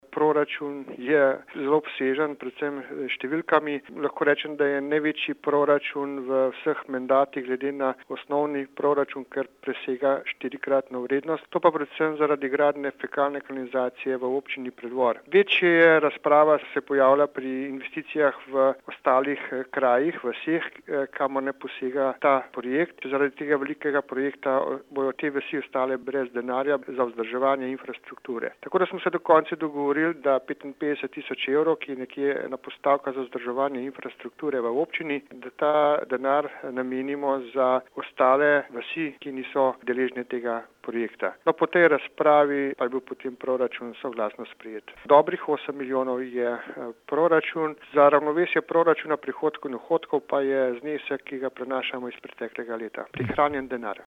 35483_izjavazupanmiranzadnikar.mp3